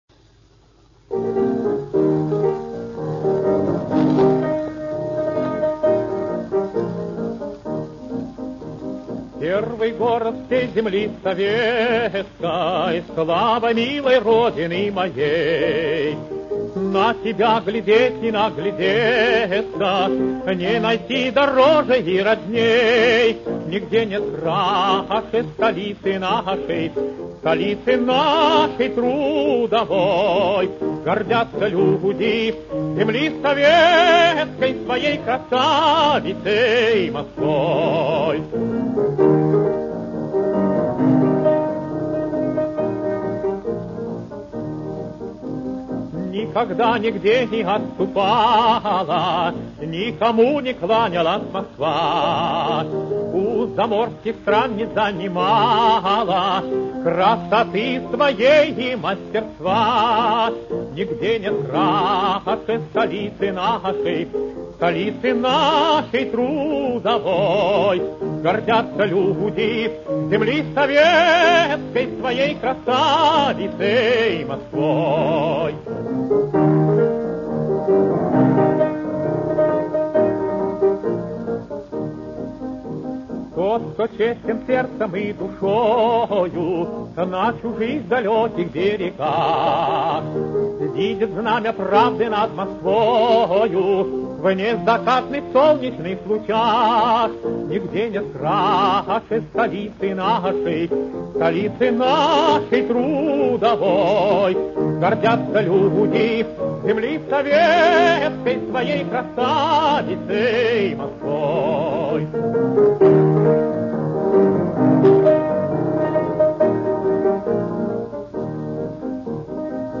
Запись с артельной пластинки